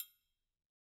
Triangle3-HitFM_v2_rr1_Sum.wav